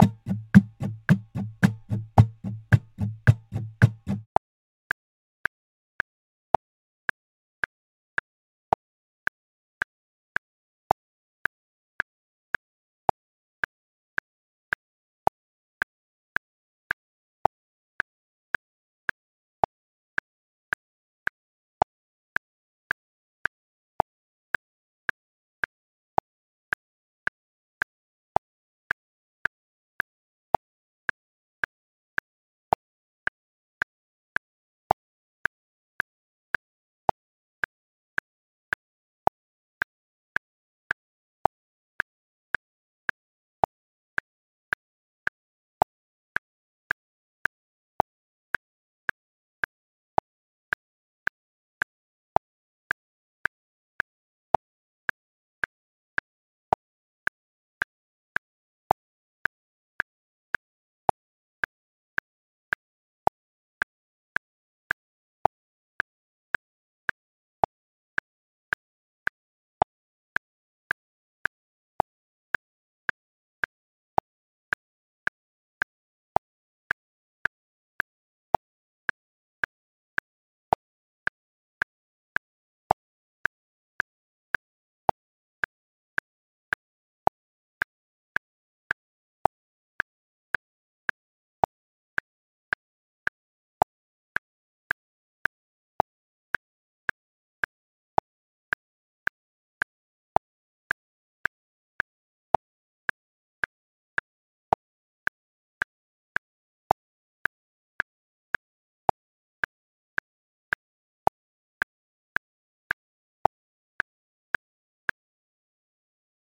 Funky rytmus
Utlum struny a hraj do metronomu:
Chytni se mě a pokračuj sám (110 bpm)
metronom-110.mp3